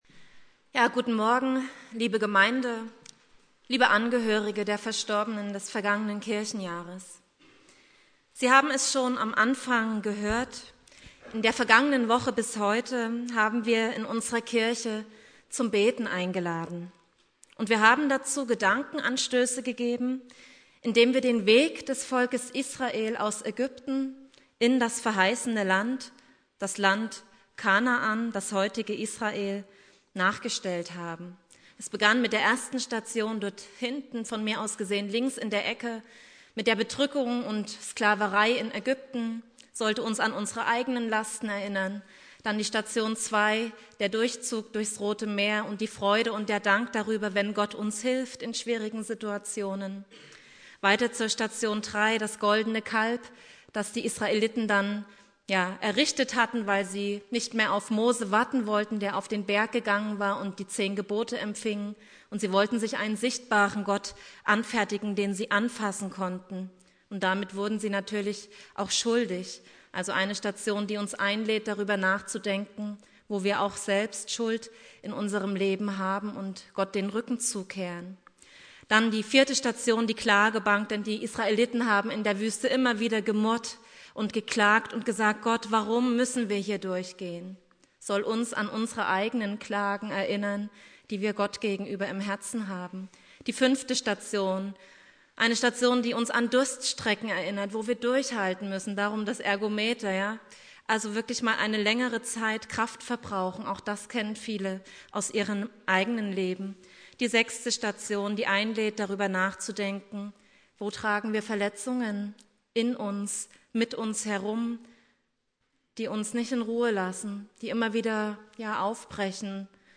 Ewigkeitssonntag
Predigt